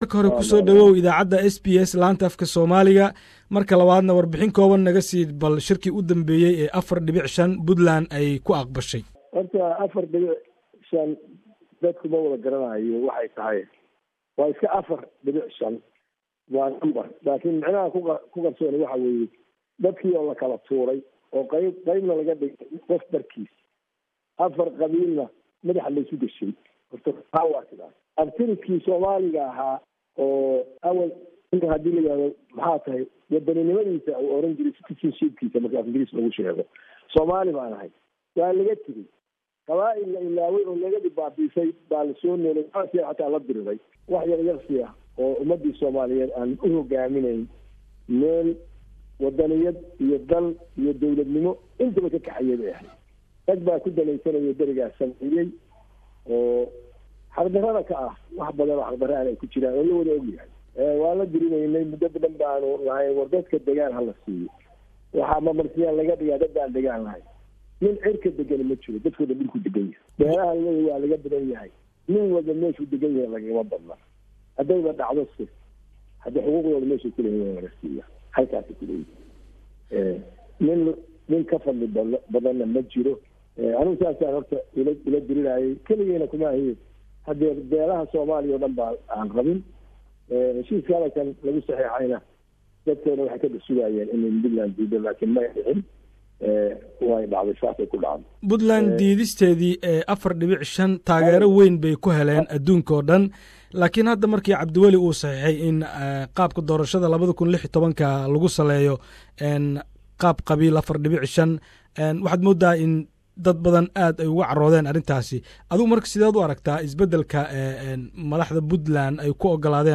Madaxweynihii Hore ee Puntland cabdiraxman faroole ayaa wareysi siiyey SBS Somali